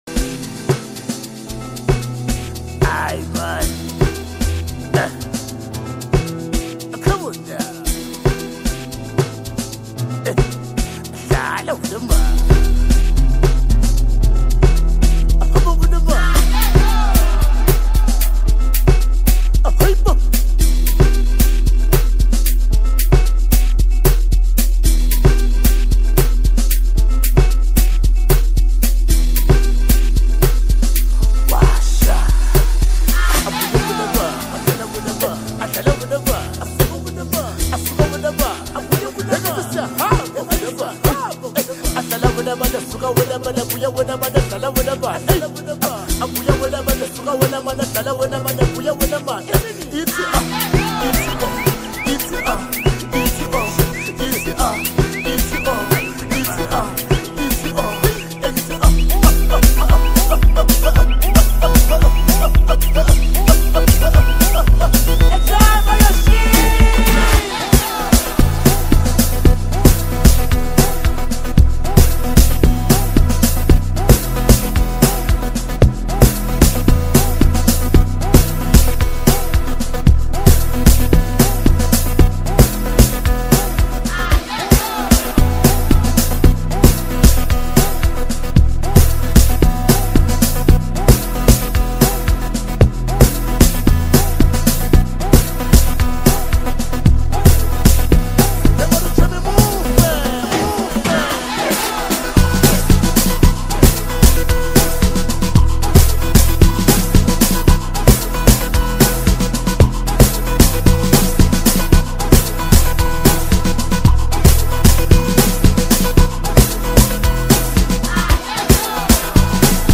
South African singer-songsmith